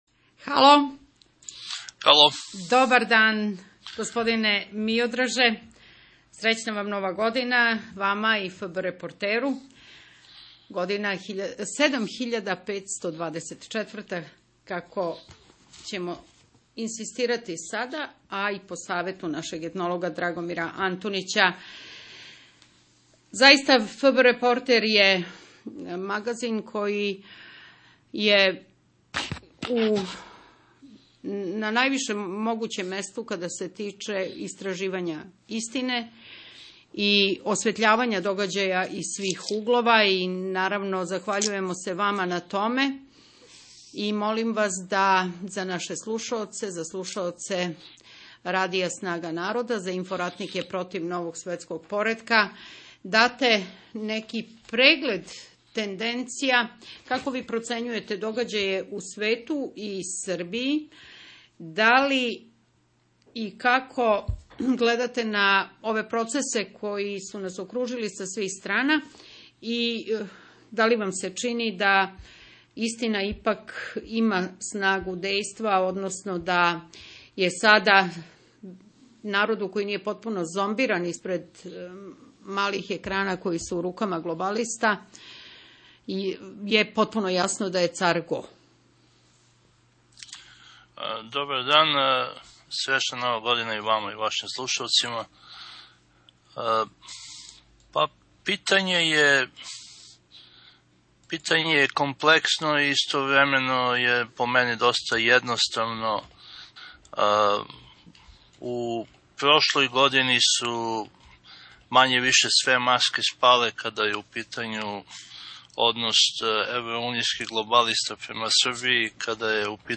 НАПОМЕНА- УВОДНИ АУДИО ПРИЛОГ ЈЕ ИЗВОД ИЗ ЕМИСИЈЕ РАДИЈА „СНАГА НАРОДА“, КОЈУ МОЖЕТЕ ДА ОДСЛУШАТЕ У ЦЕЛИНИ, СА ДОЊЕГ „ВИДЕО КЛИПА“: